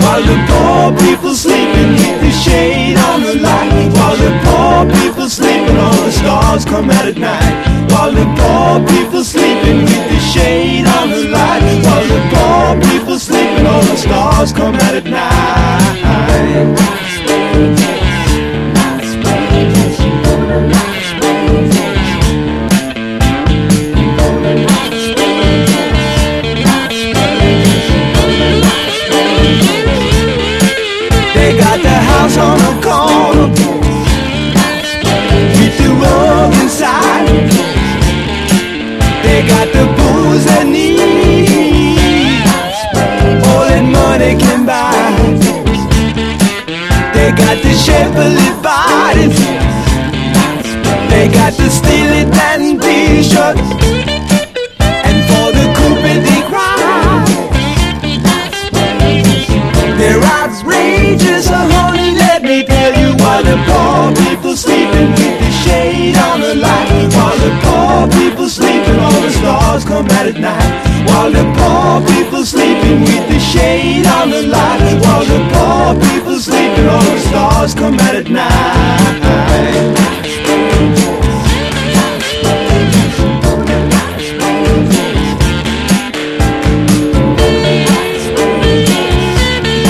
ROCK / S.S.W./A.O.R.
スライド・ギターが唸りまくるニューオリンズ風いなためファンキー・グルーヴ
ポジティヴなホーンやハーモニーがジューシーに包み込むサンシャインA.O.R.